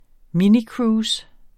Udtale [ -ˌkɹuːs ]